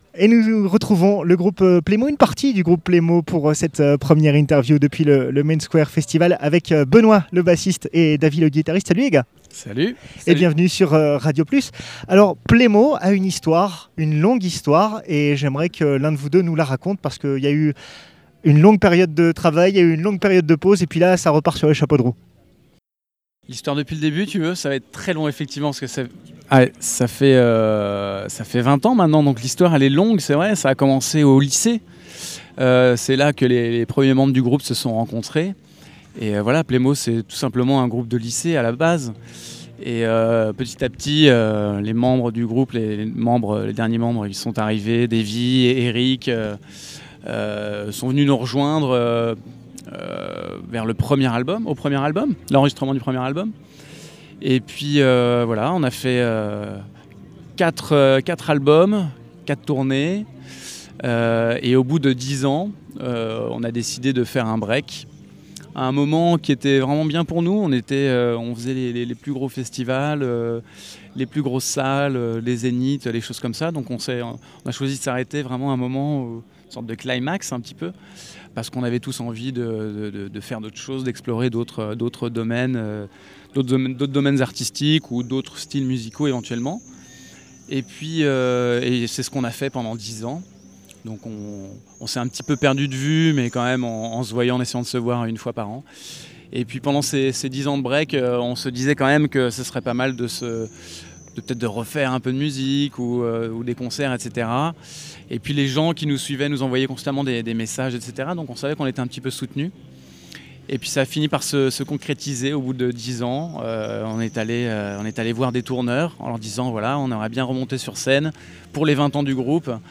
Cette année, votre radio est présente à la Citadelle d’Arras pour vivre et vous faire vivre l’événement musical majeur de l’été dans notre région : Le Main Square Festival ! Retrouvez nos comptes-rendus et nos interviews.